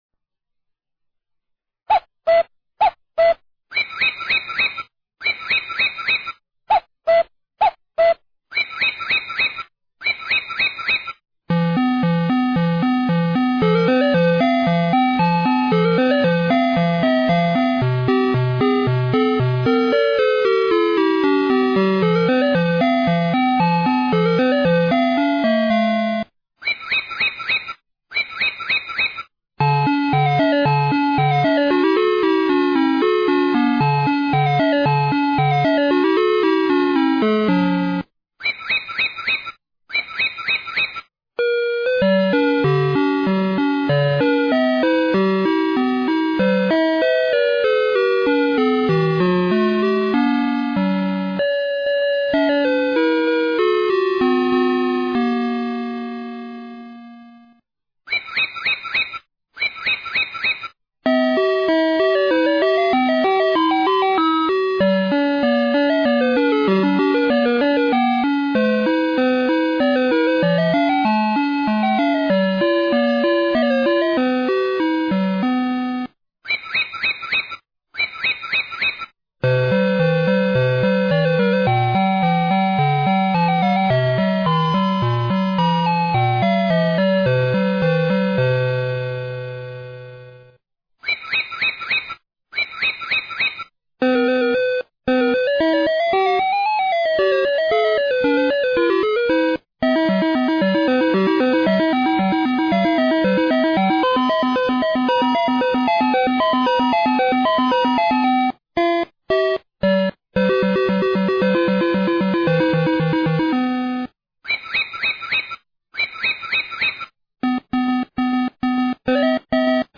双音鸟叫声+10首世界名曲
音乐曲目: 经典世界名曲
支持喇叭和蜂鸣片：喇叭是8欧0.5W　范围 :0.25W-1W的喇叭都行.
闹钟闹钟IC音乐曲目：世界名曲10首